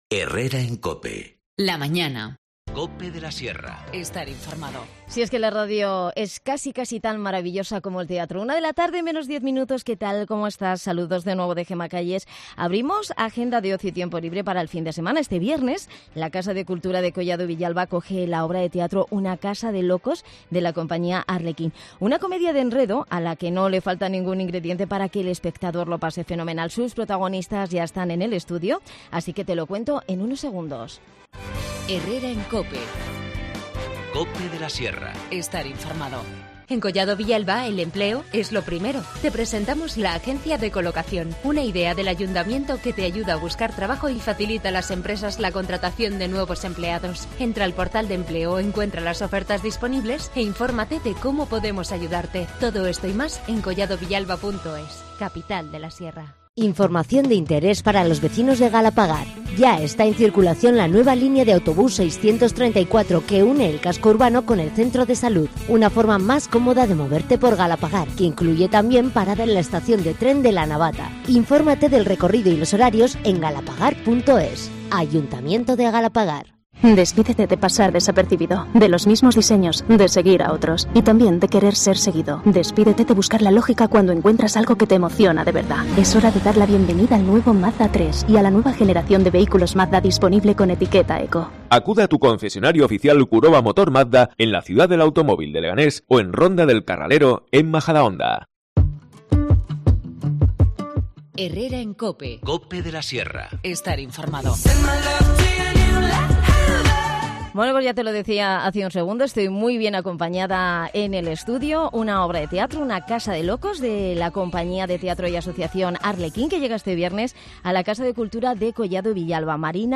nos visitan al estudio para contarnos todos los detalles.